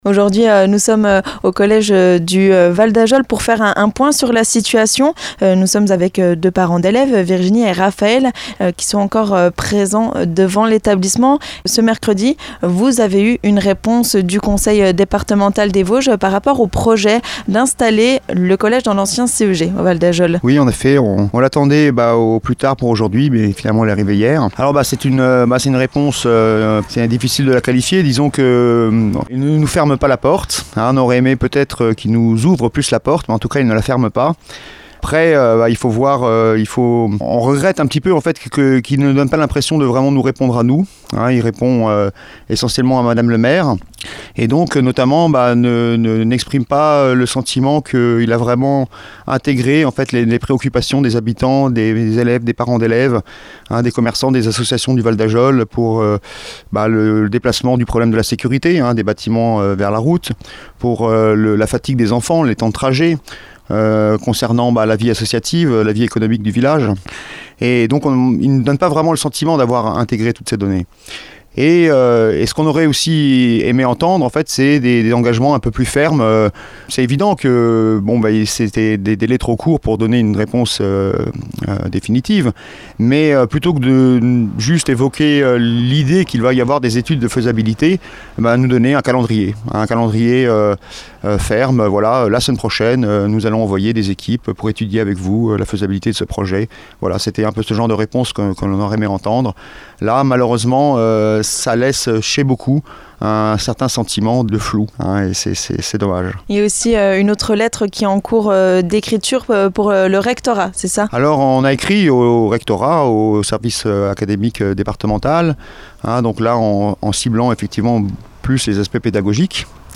Ce jeudi 1er septembre, Vosges FM est allé à la rencontre des parents d'élèves du Val-d'Ajol qui sont encore mobilisés contre la fermeture du collège. Les 122 collégiens ont fait leur rentrée ce matin, mais dans l'établissement de Plombières.